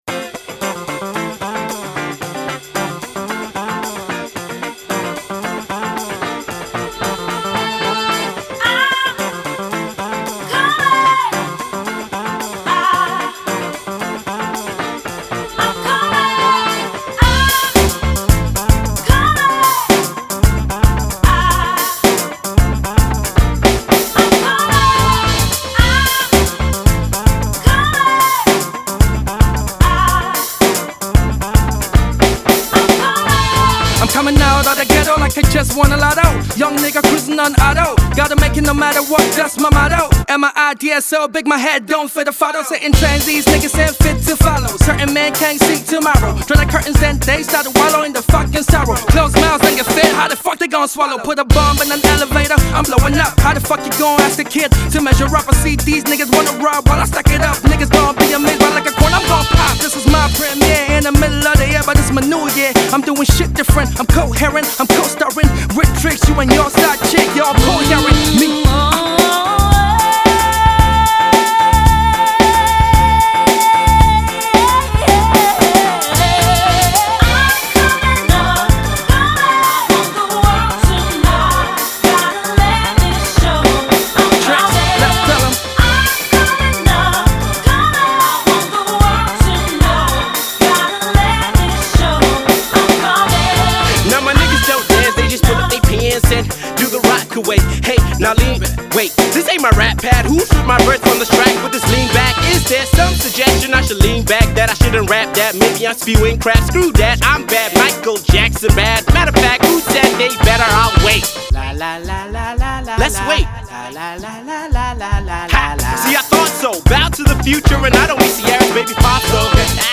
Hip-Hop
With tight bars coming from both rappers and strong vocals
Its a Solid Rap song